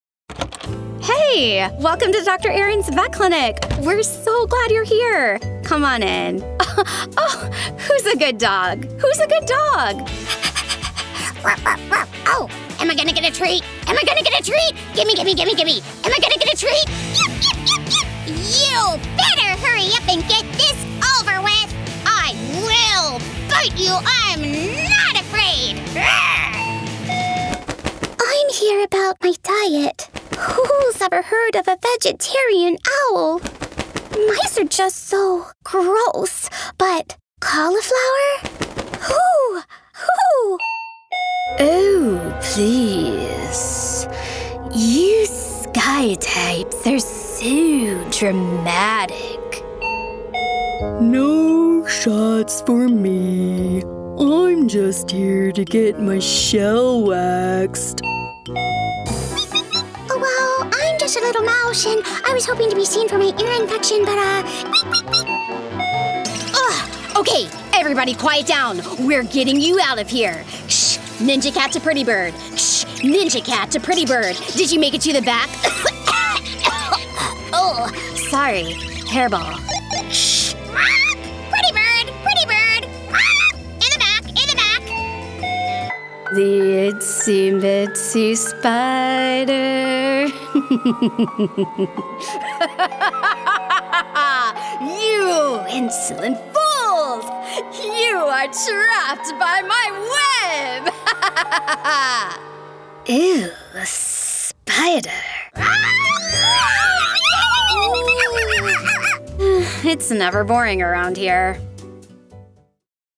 Female
She brings charm, clarity, and versatility to every read—from conversational and friendly to quirky, energetic, or sincere.
Character / Cartoon
Fun, Expressive Characters
Words that describe my voice are Youthful, Conversational, Expressive.